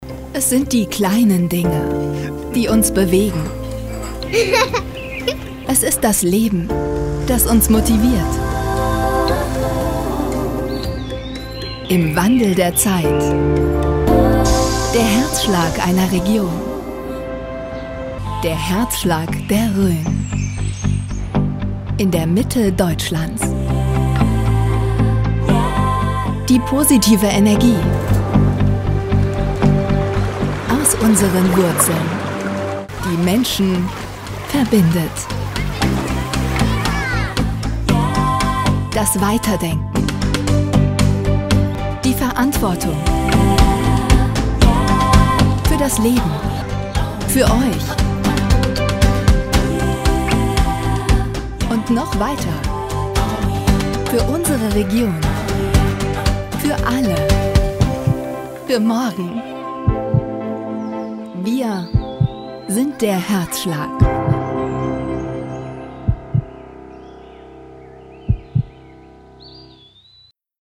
Imagefilm RhönEnergie